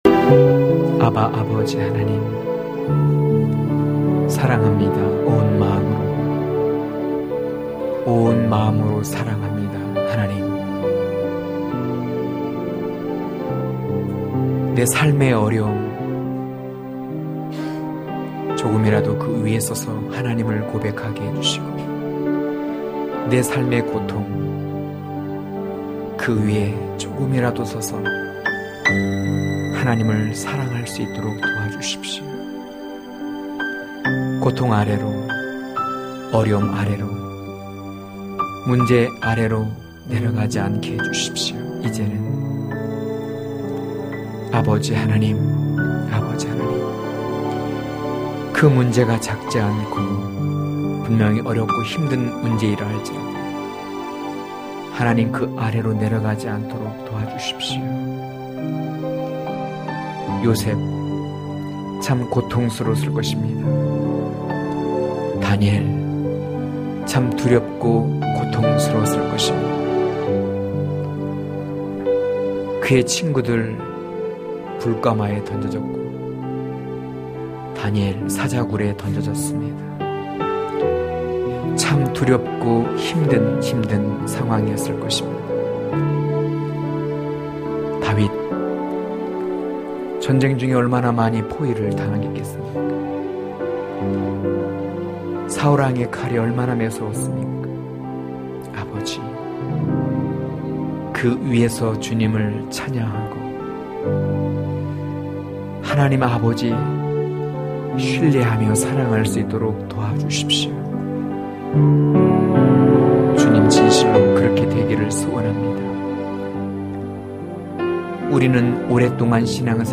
강해설교 - 05.샤론의 수선화(아2장1-4절)